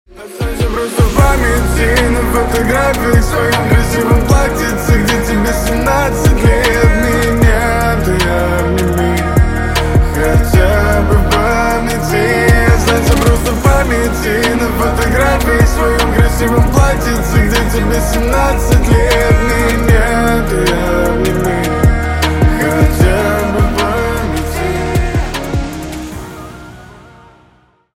# Грустные Рингтоны
# Поп Рингтоны